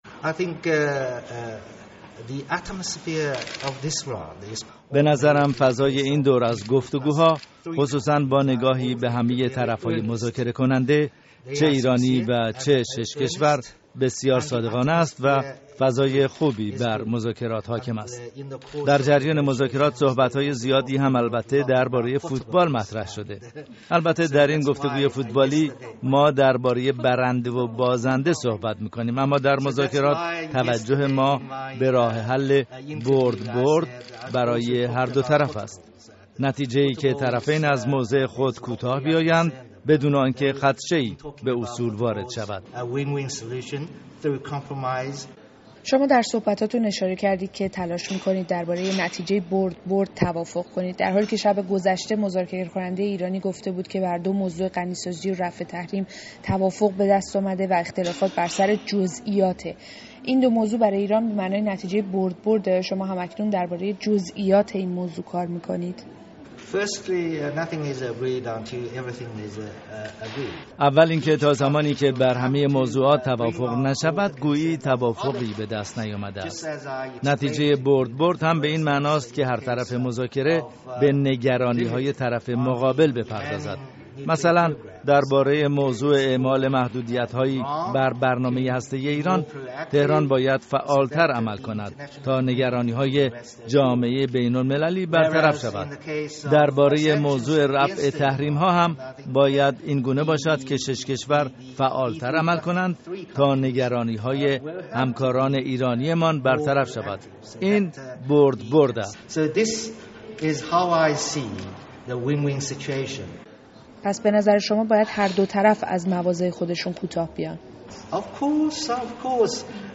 گفت‌وگوی رادیو فردا با نماینده چین در مذاکرات هسته‌ای